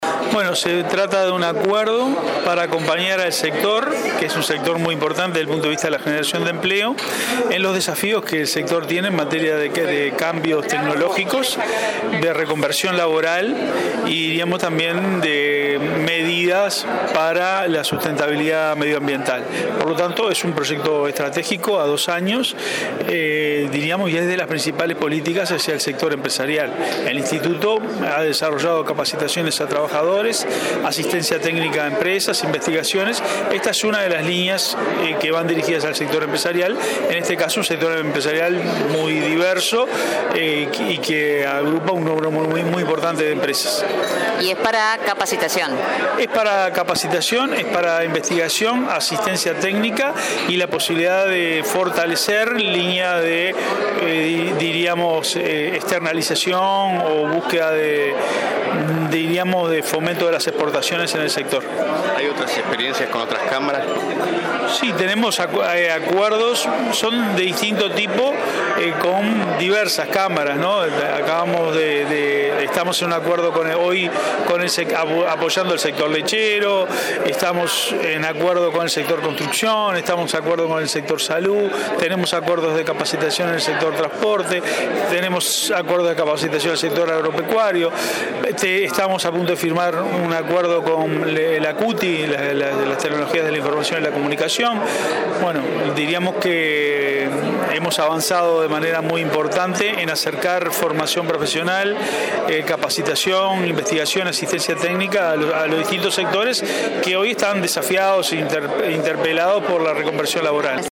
El Instituto Nacional de Empleo y Formación Profesional y la Cámara de Comercio y Servicios firmaron un acuerdo para capacitación y asistencia técnica del sector empresarial para cambios tecnológicos, reconversión laboral y medidas de sustentabilidad ambiental. Se trata de un proyecto estratégico a dos años, en el marco de una de las líneas dirigidas al sector empresarial, sostuvo el director del Inefop, Eduardo Pereyra